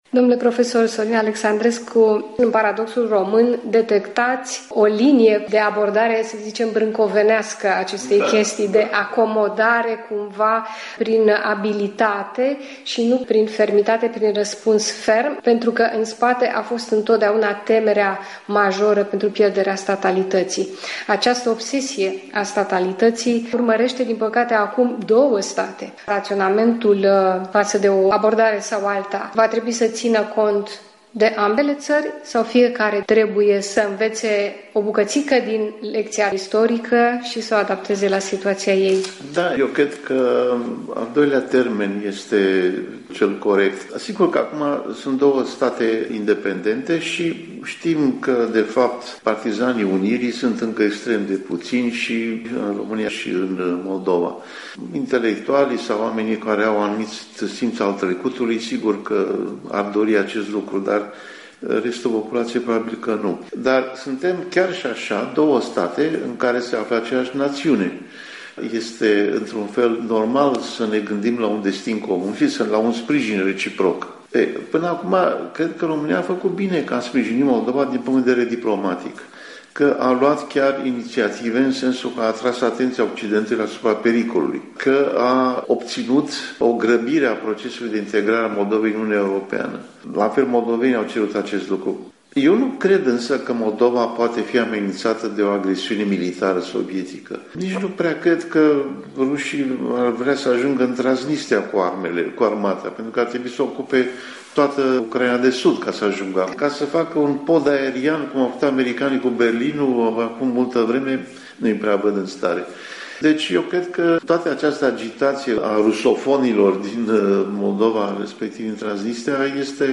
În dialog cu profesorul universitar Sorin Alexandrescu